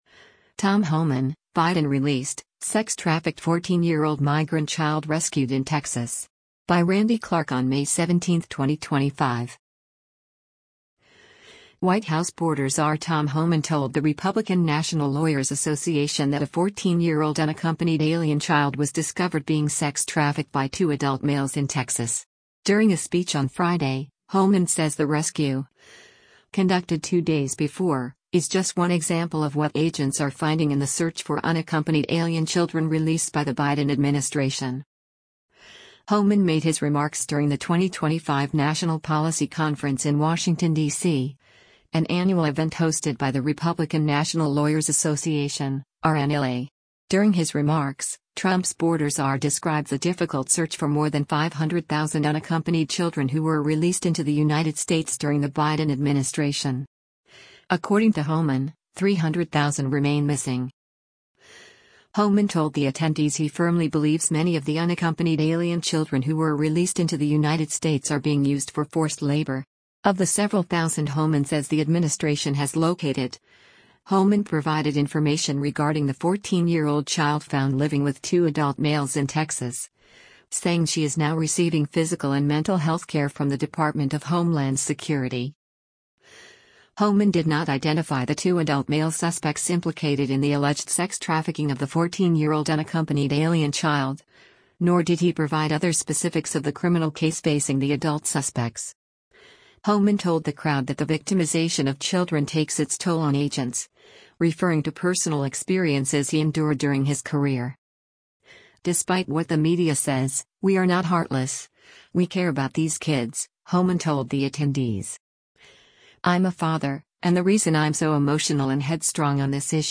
White House Border Czar Tom Homan told the Republican National Lawyers Association that a 14-year-old unaccompanied alien child was discovered being sex trafficked by two adult males in Texas.
Homan made his remarks during the 2025 National Policy Conference in Washington, D.C., an annual event hosted by the Republican National Lawyers Association (RNLA).